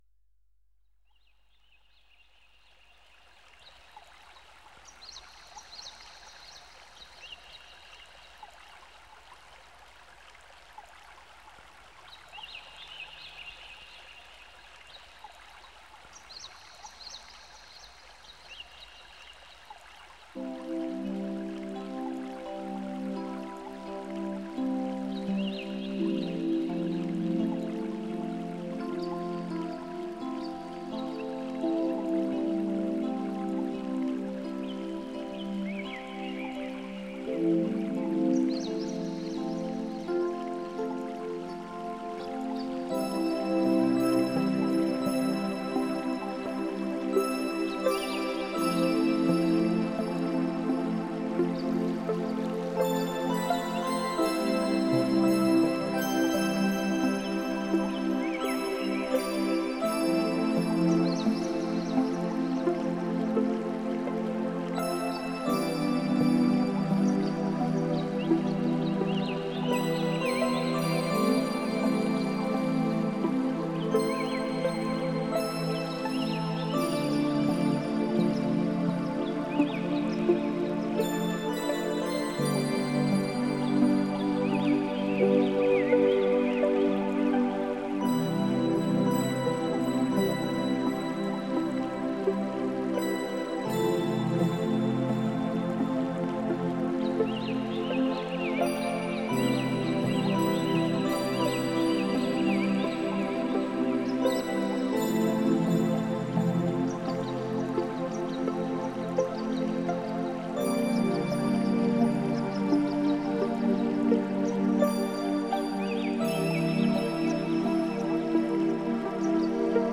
специальностью которой стал мягкий синтезаторный new age.